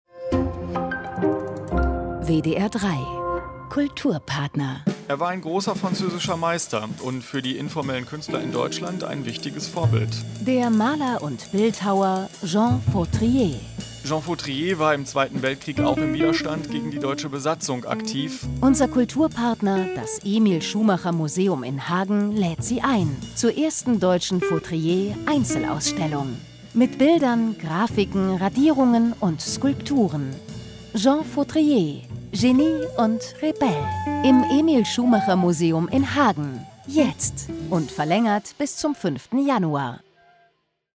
40 Sekunden-Trailer